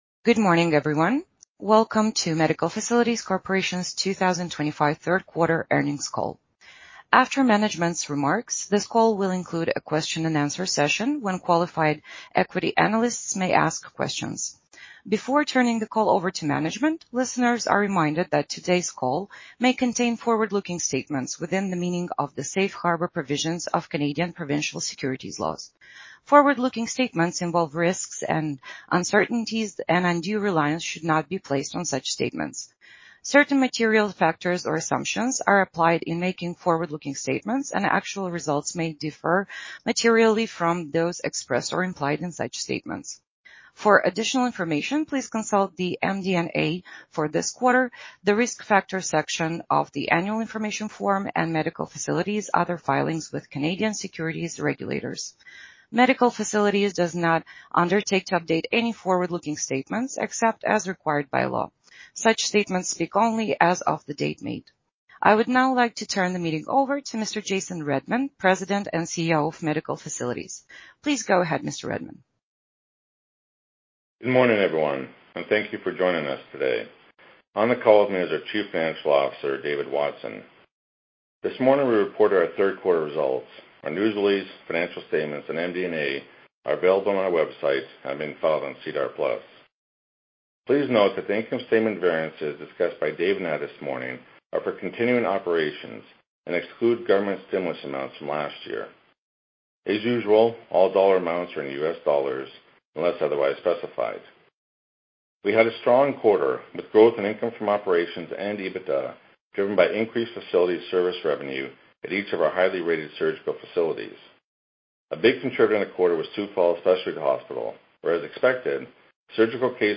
MFC Q3 2025 earnings call audio.mp3